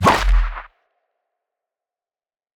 Toon punch 1.wav